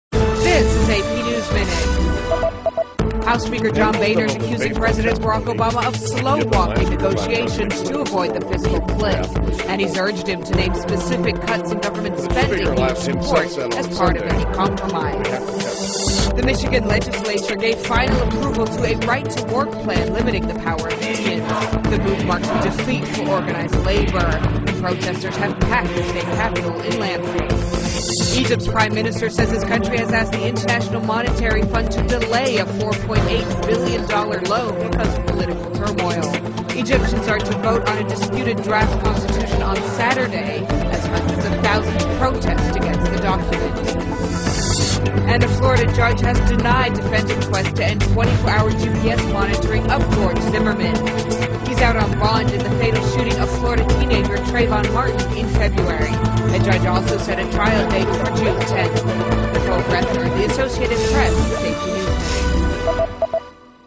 电视新闻片长一分钟，一般包括五个小段，简明扼要，语言规范，便于大家快速了解世界大事。